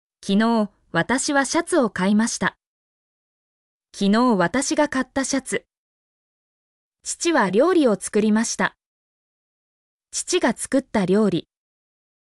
mp3-output-ttsfreedotcom-12_WISsd9Pk.mp3